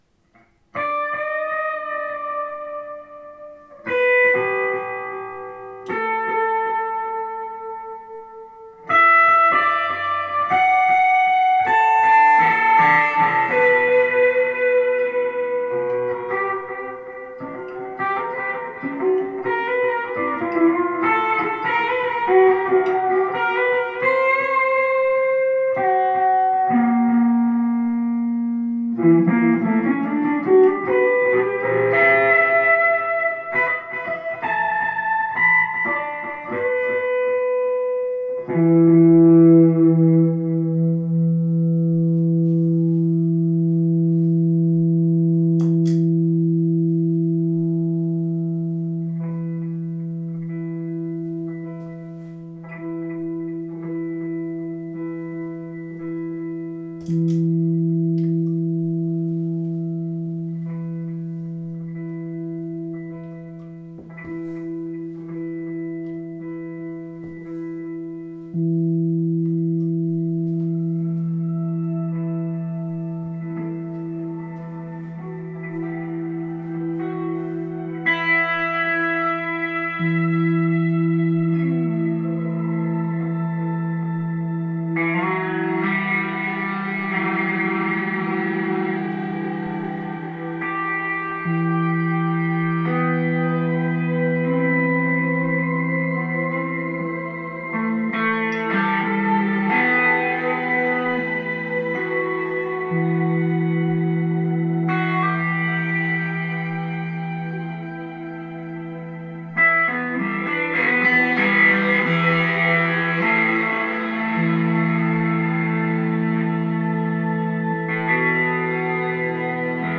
Eine kleine Feierabend-Improvisation für Sologitarre
Gitarre